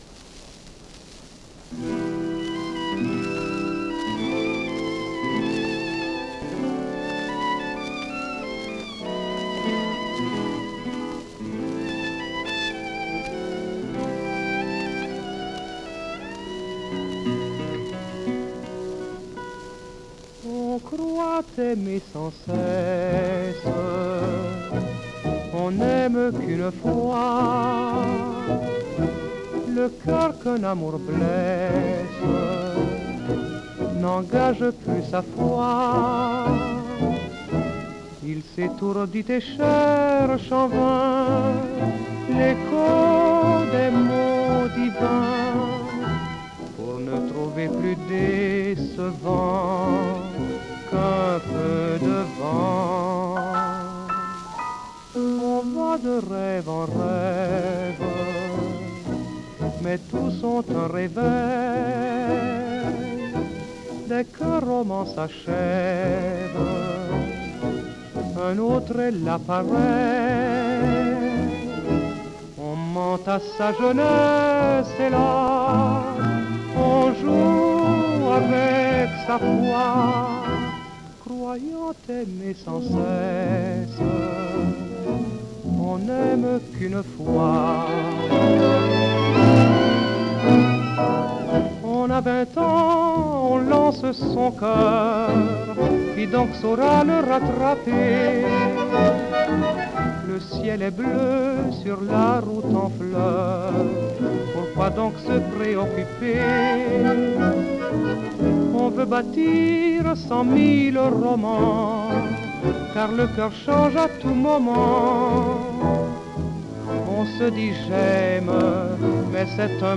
with the Tango